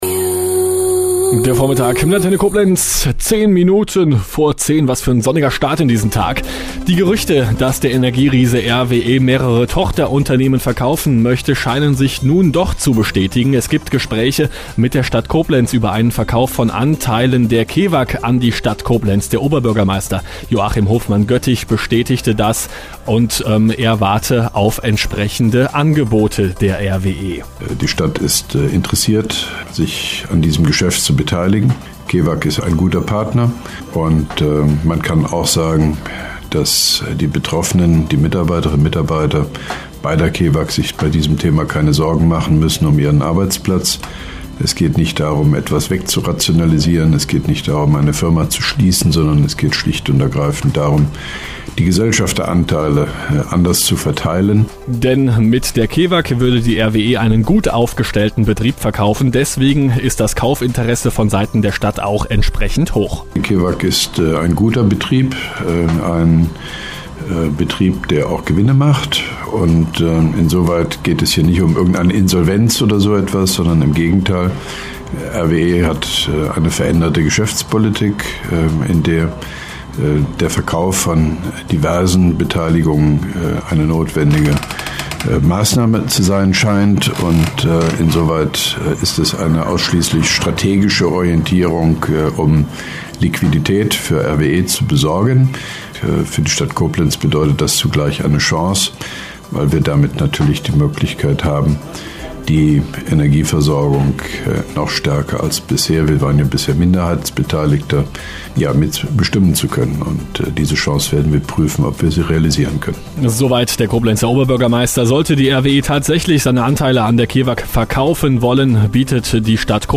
Mit Stellungnahme von OB Hofmann-Göttig zum Ist-Stand